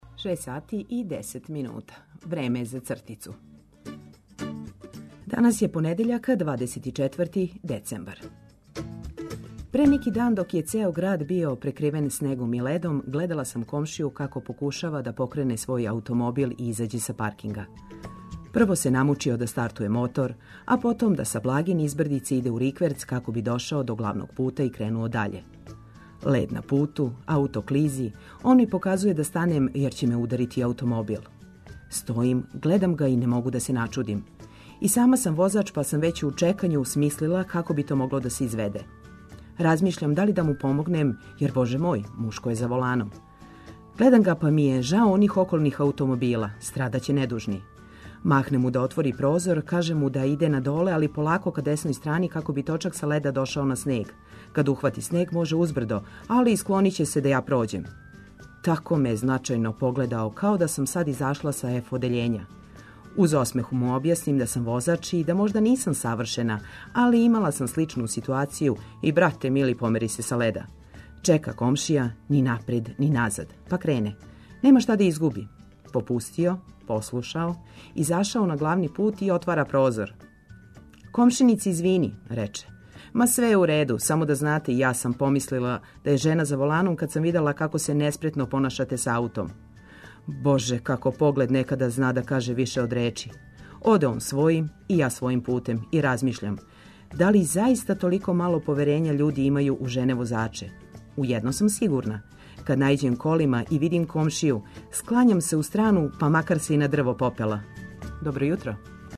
Наравно да је ту и одабрана музика, као још један аргумент за устајaње.